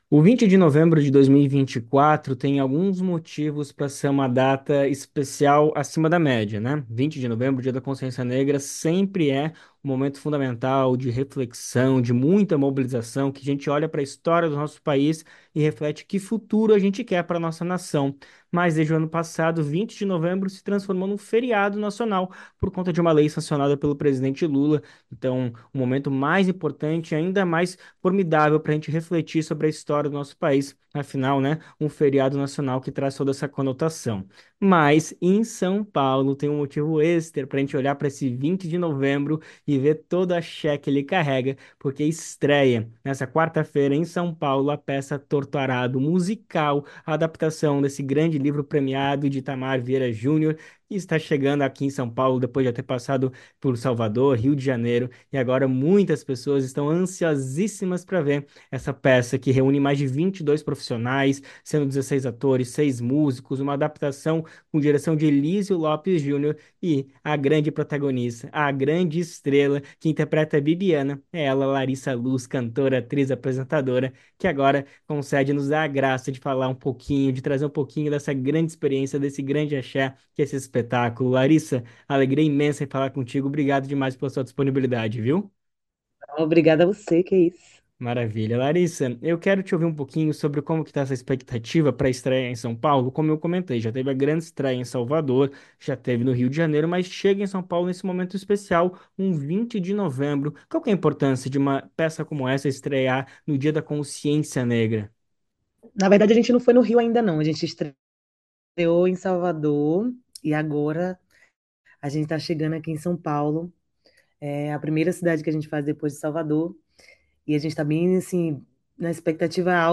Conversa Bem Viver Musical Torto Arado estreia em SP com Larissa Luz levando coragem e transgressão de Elza Soares aos palcos Artista comenta sobre emoção de estrear espetáculo no 20 de novembro com ingressos esgotados para toda temporada